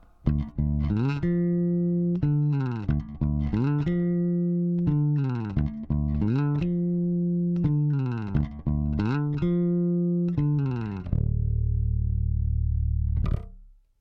ベースラインでこの曲なーんだ